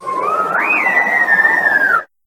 Grito de Tapu Fini.ogg
) Categoría:Gritos de Pokémon de la séptima generación No puedes sobrescribir este archivo.
Grito_de_Tapu_Fini.ogg.mp3